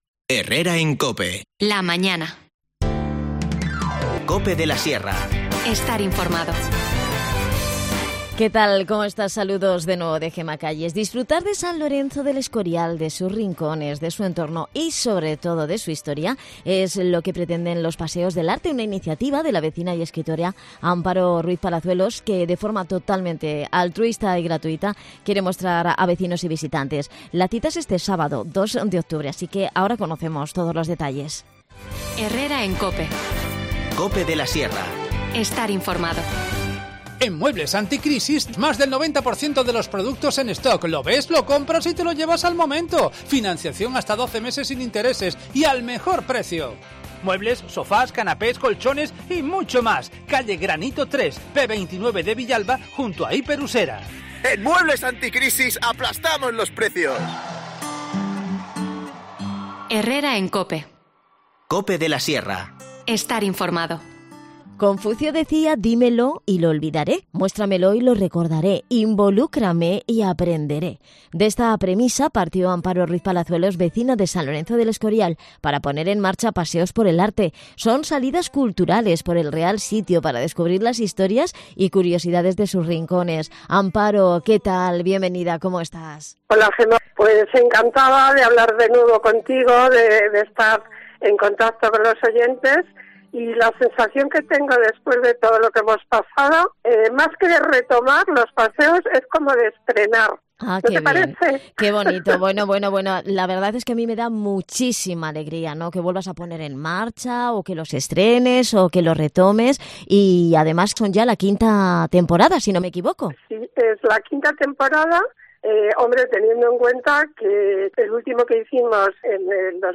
Charlamos con ella en el programa.